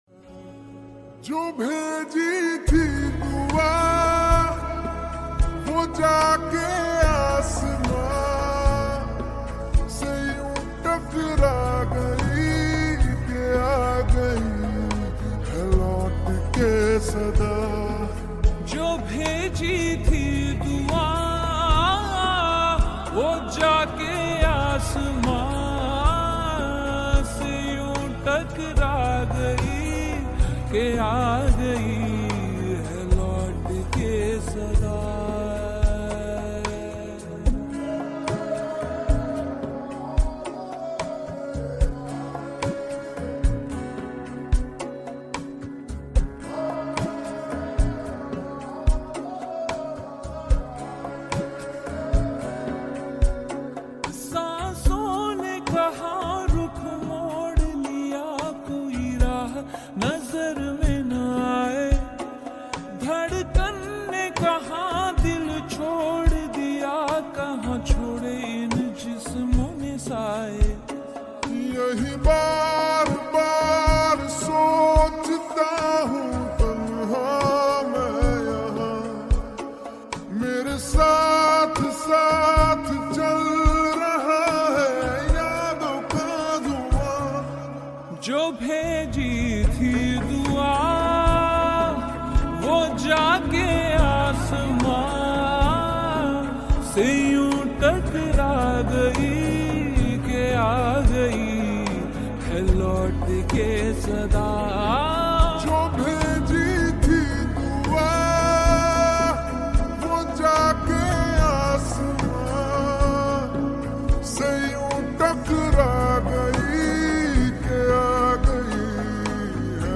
slow and reverb